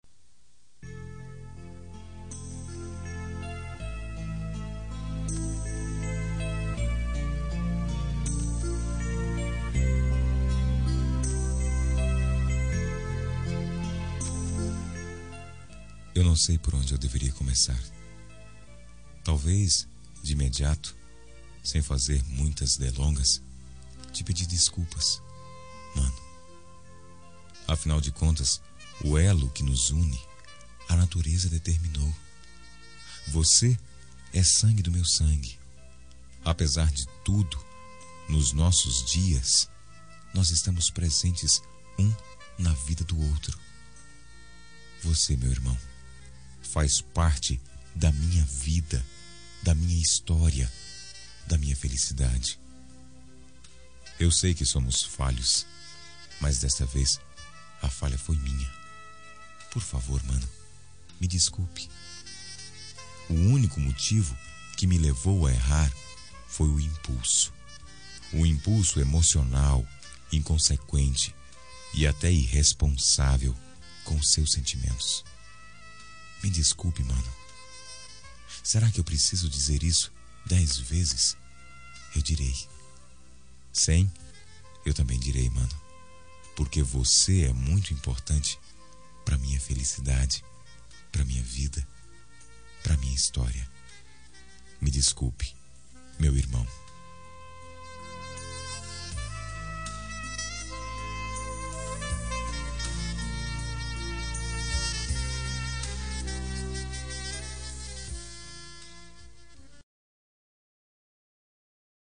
Reconciliação Familiar – Voz Masculina – Cód: 088739 – Irmão